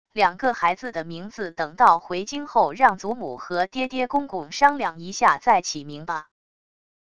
两个孩子的名字等到回京后让祖母和爹爹公公商量一下再起名吧wav音频生成系统WAV Audio Player